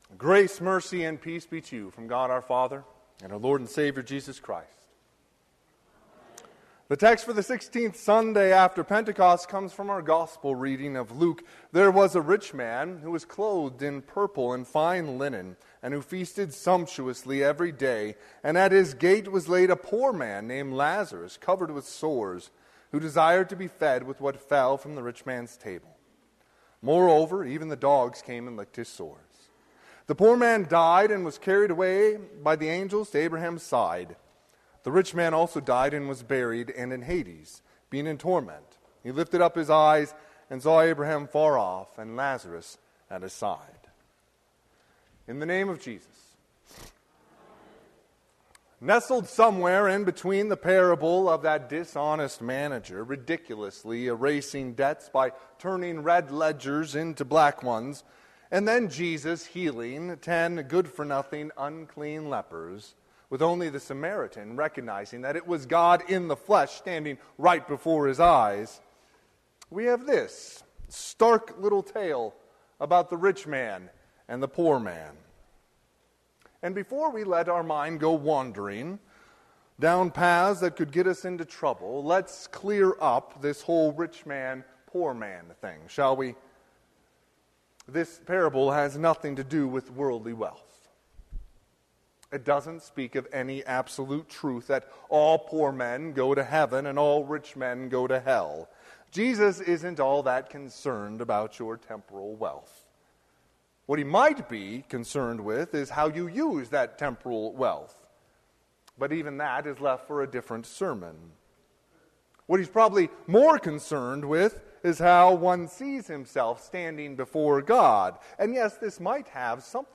Sermon - 9/25/2022 - Wheat Ridge Lutheran Church, Wheat Ridge, Colorado
Sixteenth Sunday after Pentecost